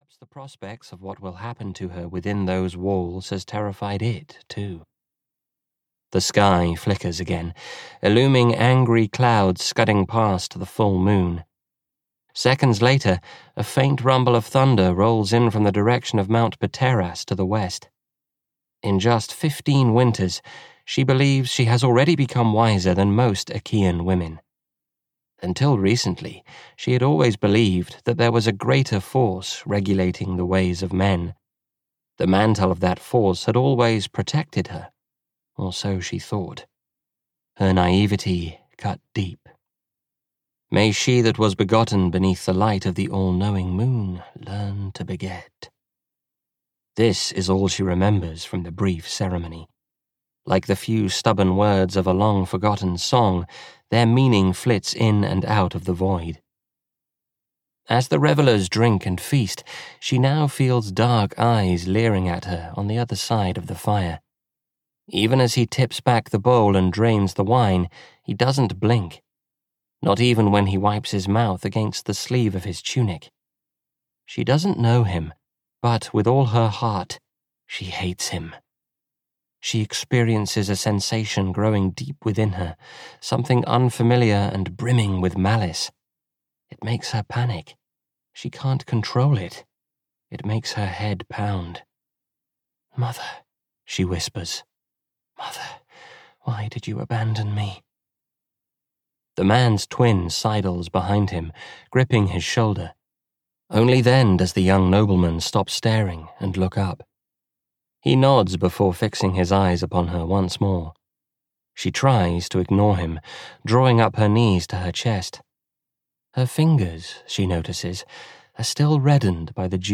Jason (EN) audiokniha
Ukázka z knihy